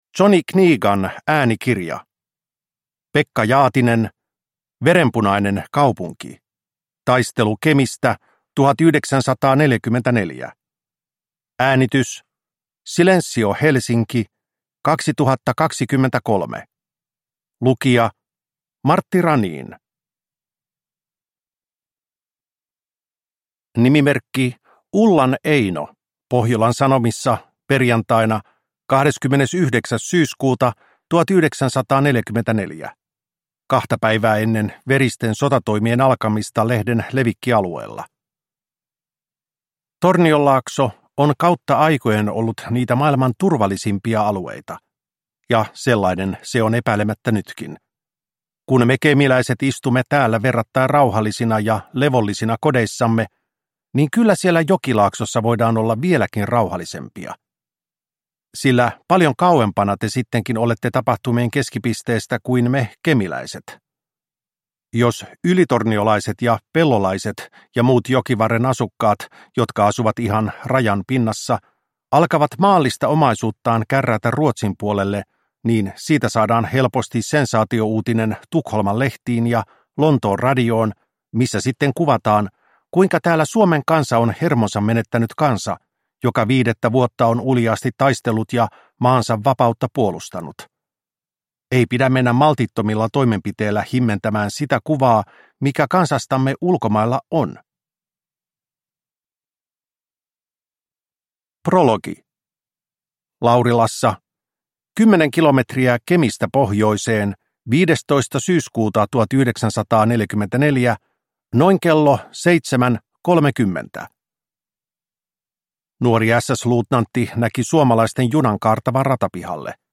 Verenpunainen kaupunki – Ljudbok